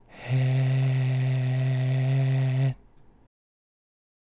(2)パー＝声帯が触れるギリギリのラインを保った、限りなく弱い閉じの声
息をはきながら少しだけ声を混ぜ、囁いているような息漏れ声が保てたらOKです。
※見本のパーの声